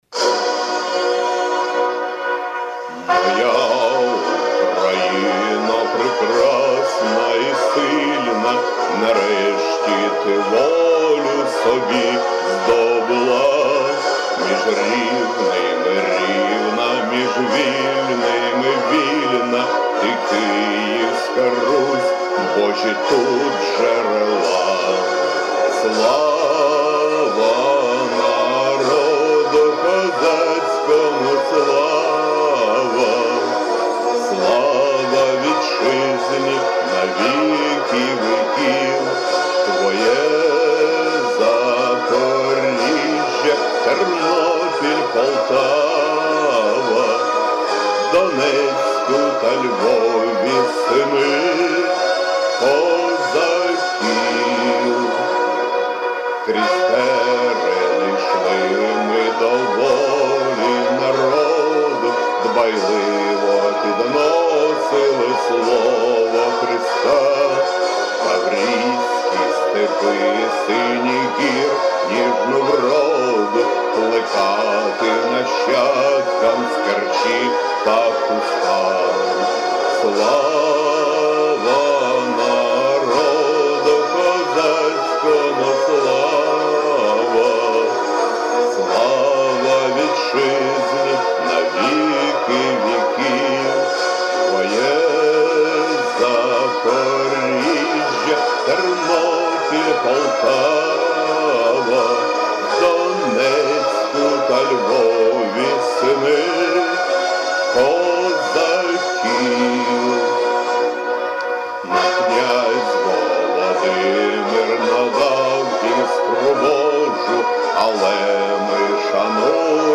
Украинский священник сочинил новый государственный гимн – бодрый, оптимистичный, на музыку советского времени